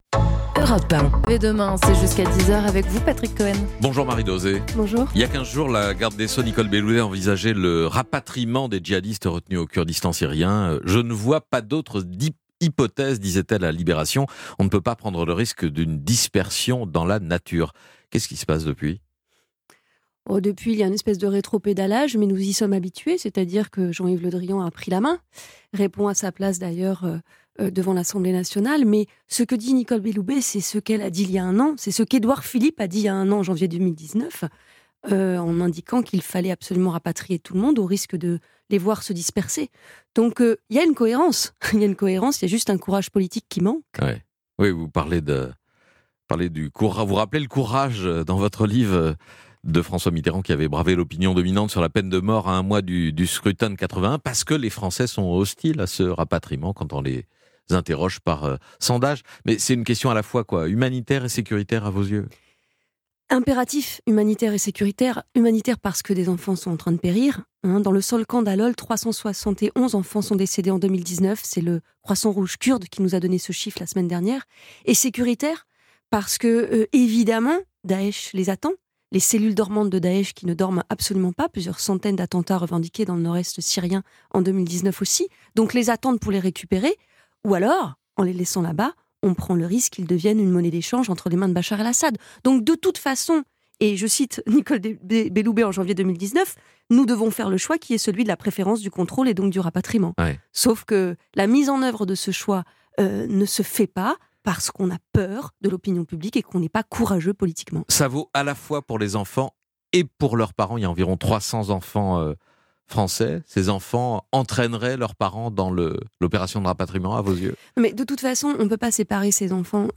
Diffusé sur Europe 1 le 26 janvier 2020
Interview